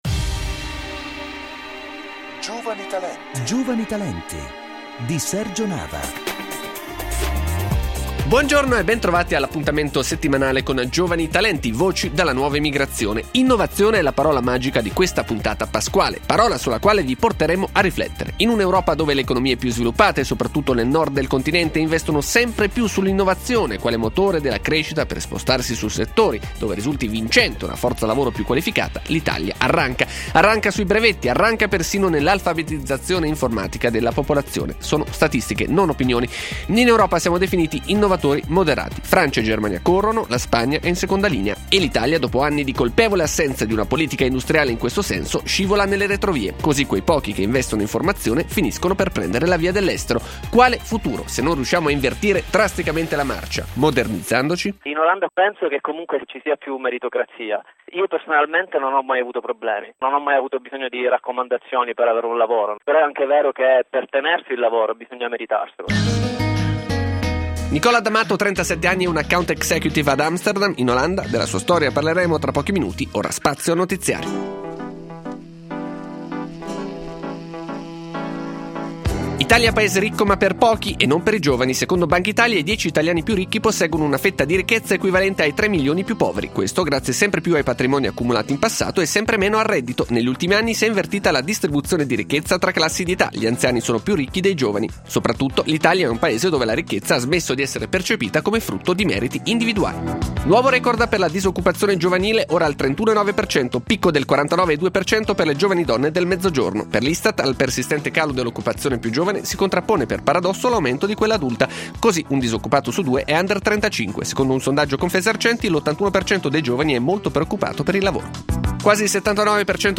Per chi non avesse potuto ascoltarla in diretta, potete ascoltare l’intervista con questo M3P